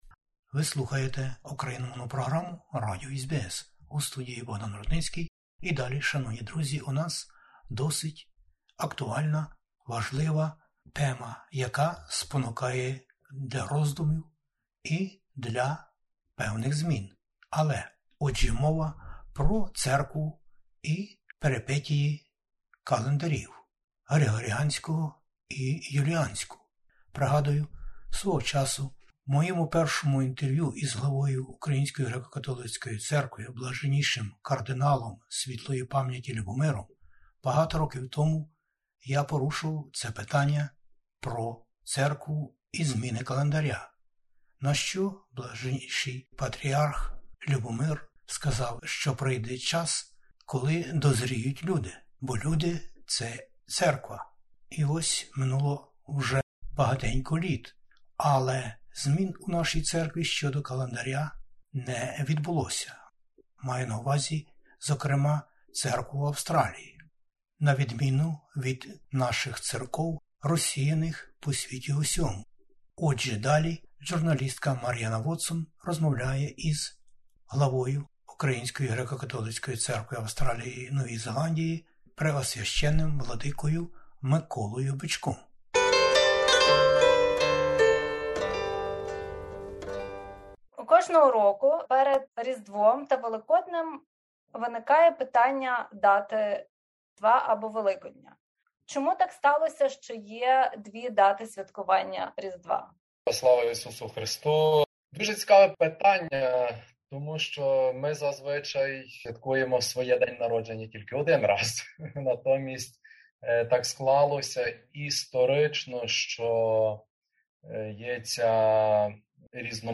розмовляє із Преосв. Владикою Кир Миколою Бичком, Главою УГКЦ в Австралії, Новій Зеландії та країнах Океанії